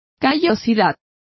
Complete with pronunciation of the translation of callus.